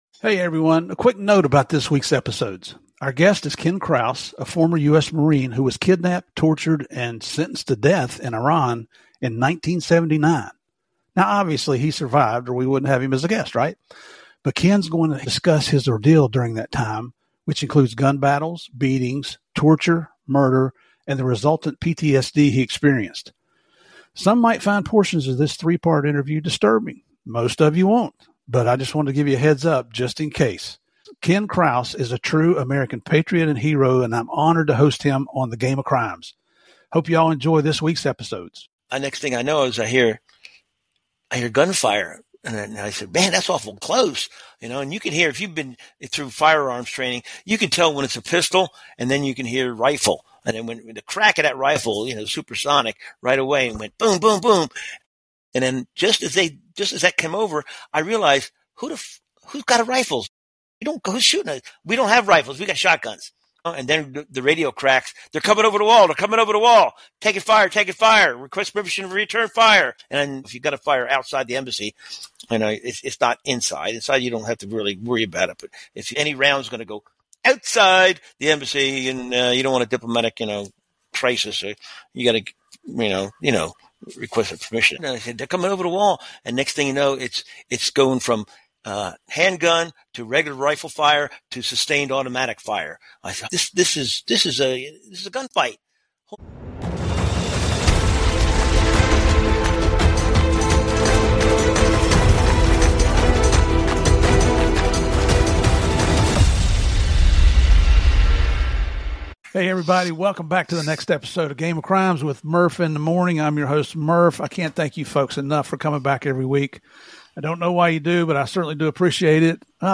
a former Marine and combat veteran
From the moment the attack began to the brutal aftermath, this is a firsthand account of military heroism , sacrifice , and survival behind enemy lines .